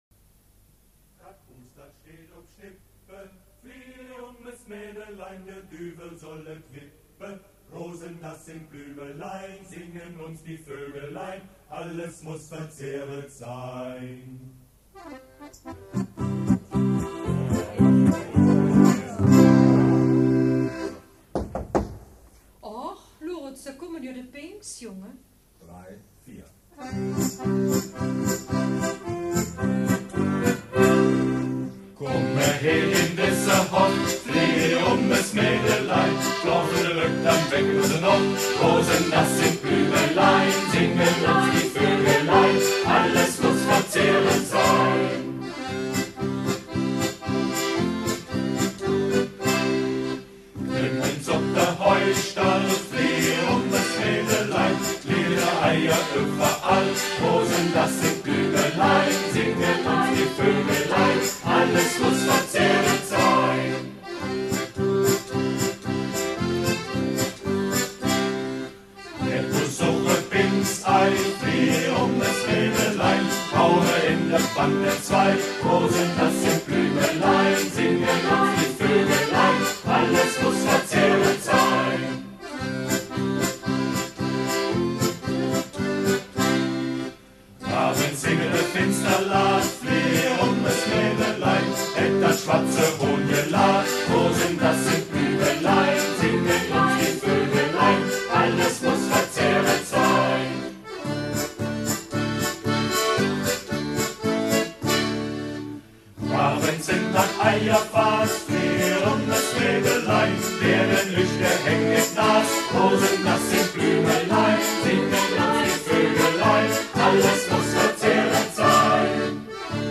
Es singt der Singkreis Möschtijall; die Aufnahme stammt aus dem Jahr 1990.